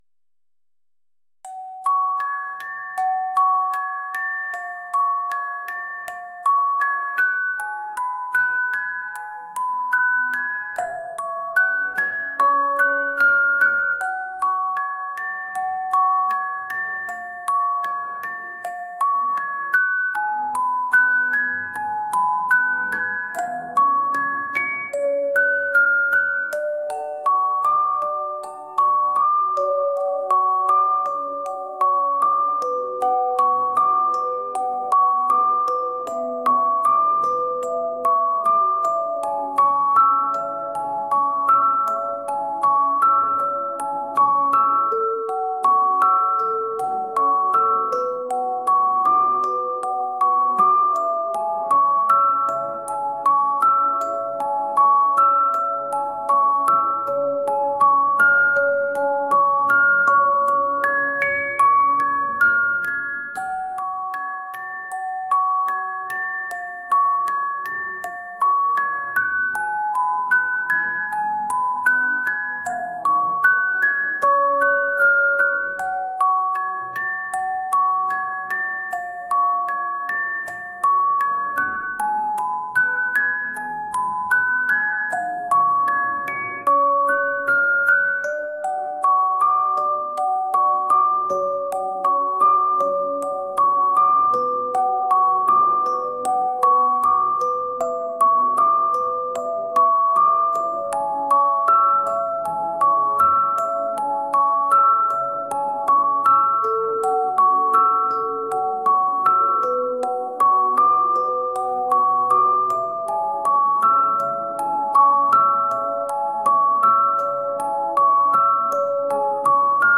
オルゴール